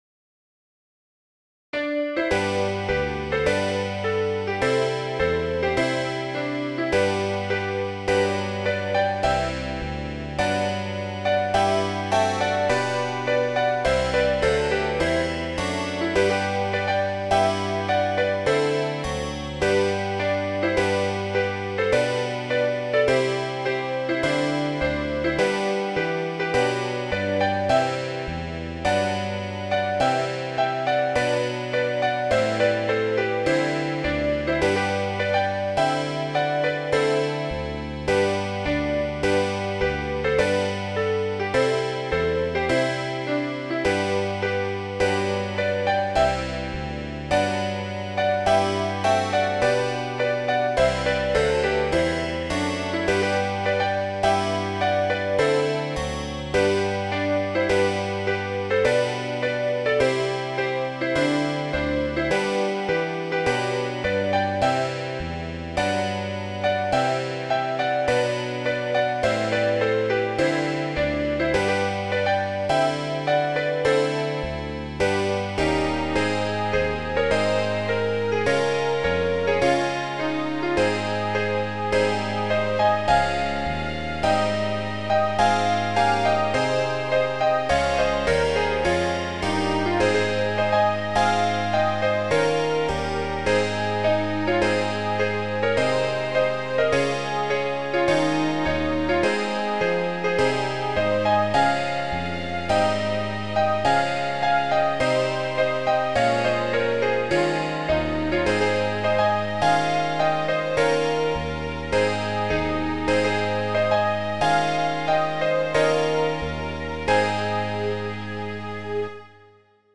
Loch Lomond is an old Jacobite Air.
llomond.mid.ogg